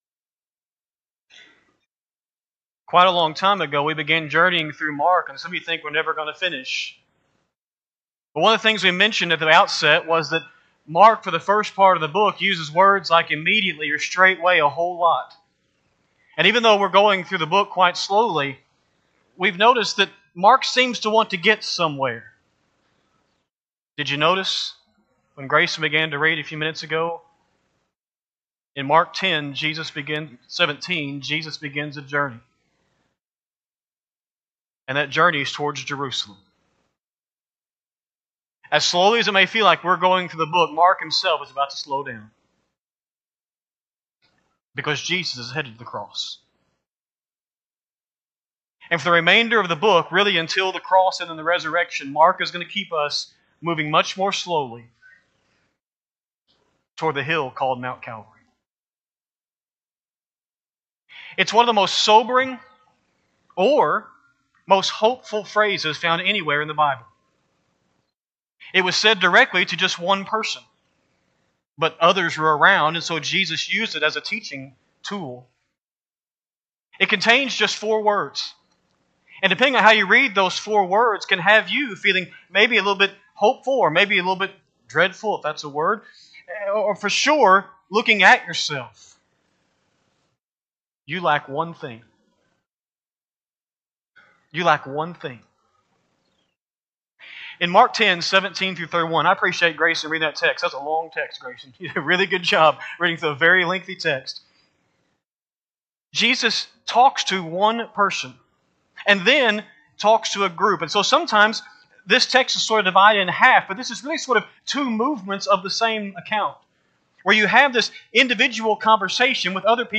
4-28-24-Sunday-AM-Sermon.mp3